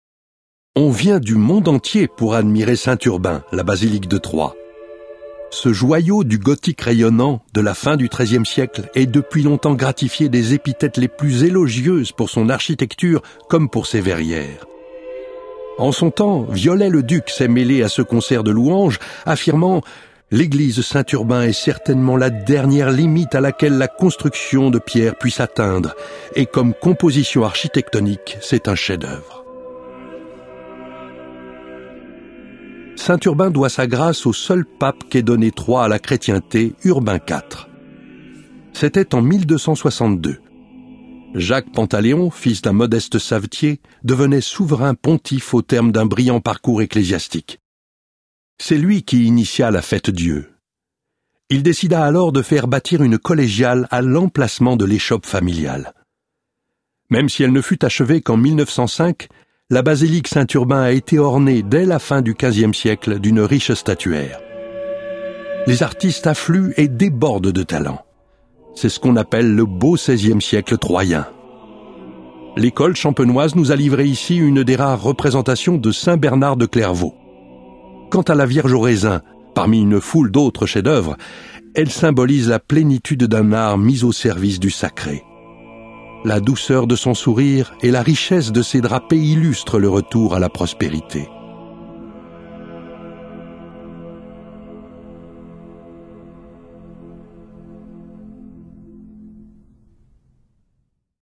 Cette balade audio-guidée vous permet de découvrir par vous-même tous les lieux importants de la ville de Troyes, tout en bénéficiant des explications de votre guide touristique numérique.